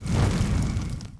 walk_act_1.wav